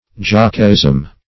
Jockeyism \Jock"ey*ism\, n. The practice of jockeys.